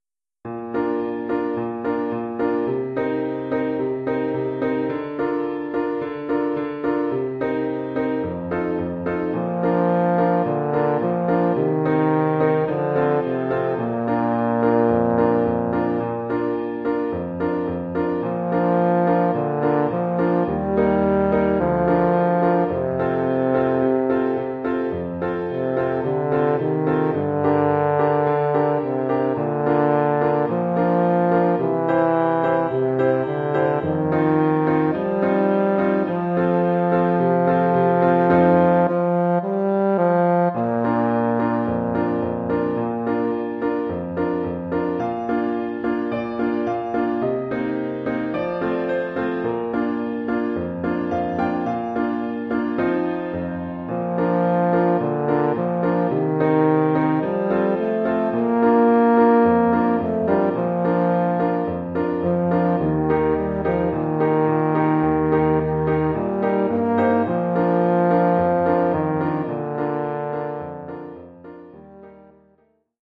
tuba et piano.